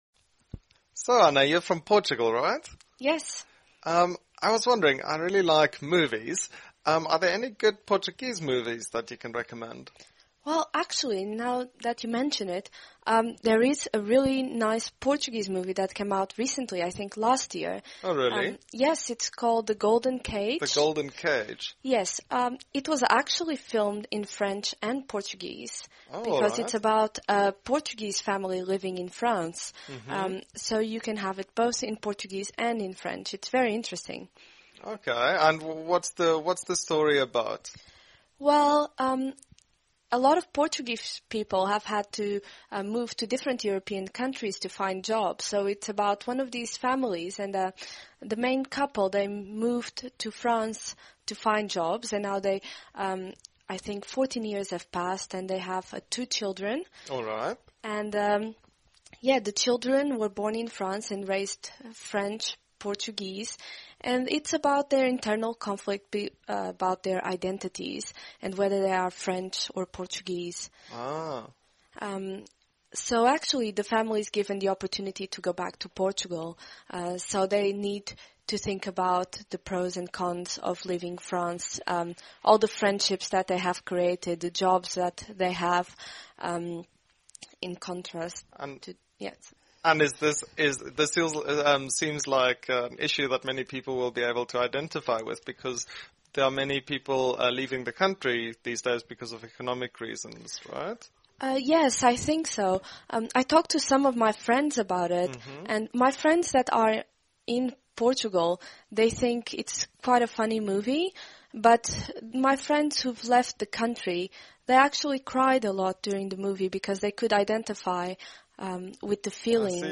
实战口语情景对话 第1070期:The Gilded Cage 金丝笼